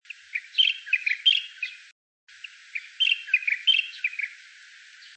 12-2墾丁烏頭翁2.mp3
物種名稱 烏頭翁 Pycnonotus taivanus
錄音地點 屏東縣 恆春鎮 墾丁青年活動中心
25-30 錄音環境 海岸林 發聲個體 行為描述 鳴唱 錄音器材 錄音: 廠牌 Denon Portable IC Recorder 型號 DN-F20R 收音: 廠牌 Sennheiser 型號 ME 67 標籤/關鍵字 鳥 備註說明 MP3檔案 12-2墾丁烏頭翁2.mp3